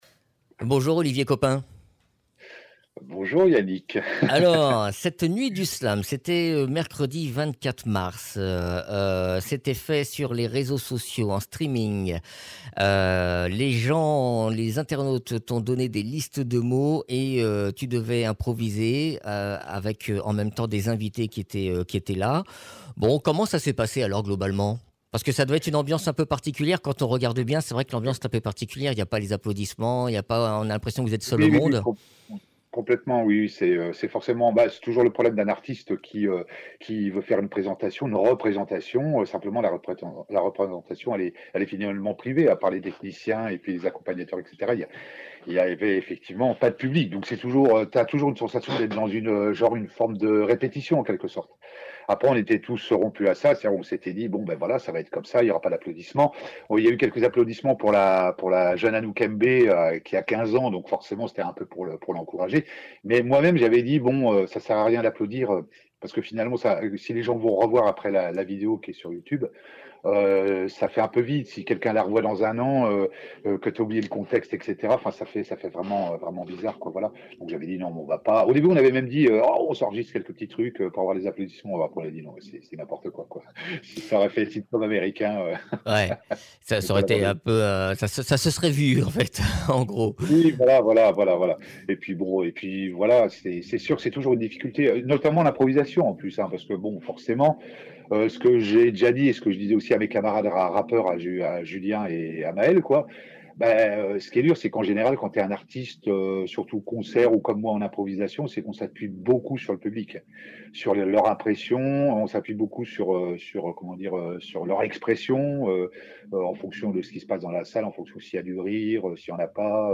Improvisation en slam réussie
Interviews